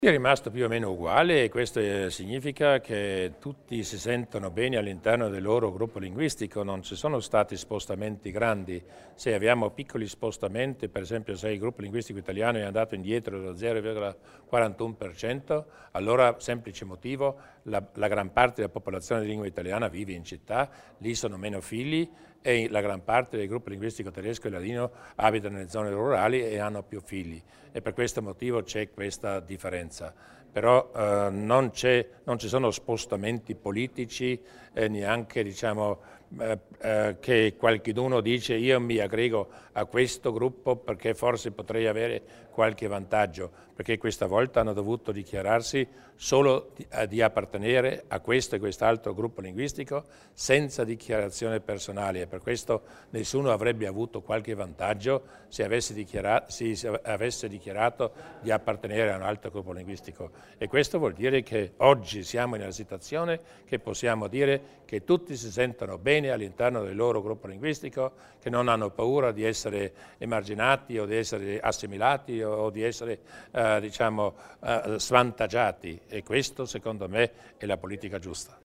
Il Presidente Durnwalder illustra i dati più importanti del censimento linguistico